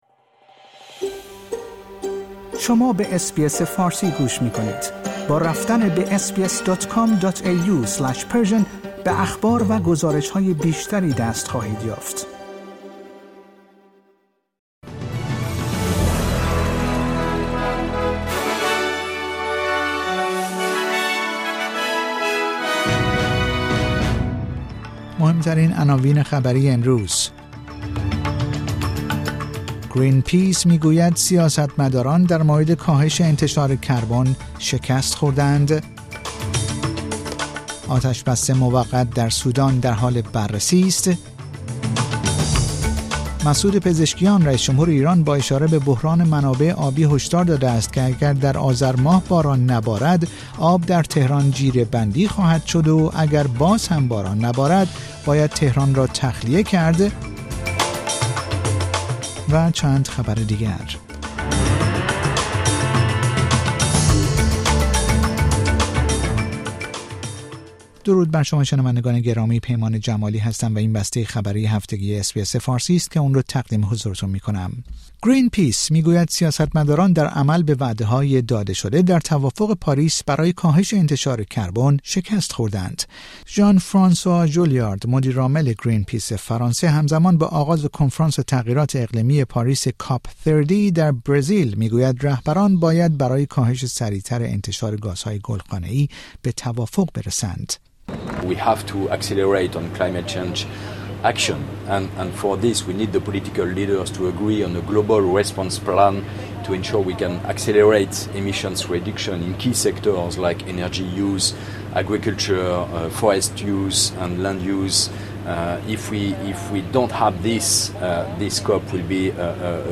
در این پادکست خبری مهمترین اخبار هفته منتهی به جمعه ۷ نوامبر ۲۰۲۵ ارائه شده است.